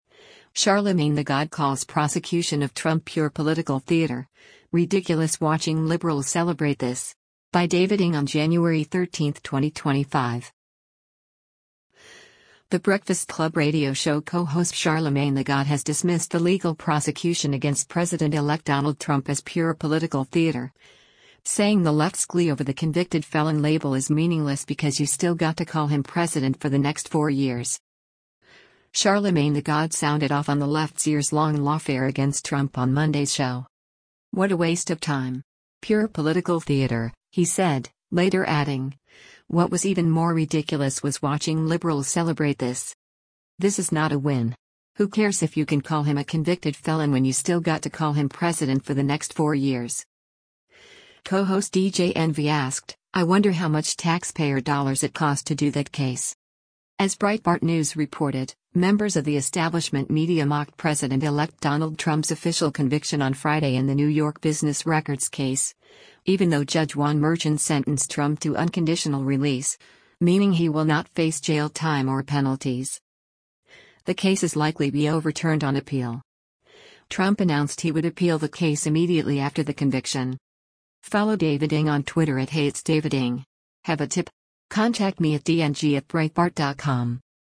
The Breakfast Club radio show co-host Charlamagne tha God has dismissed the legal prosecution against President-elect Donald Trump as “pure political theater,” saying the left’s glee over the “convicted felon” label is meaningless because “you still got to call him President for the next four years.
Charlamagne tha God sounded off on the left’s years-long lawfare against Trump on Monday’s show.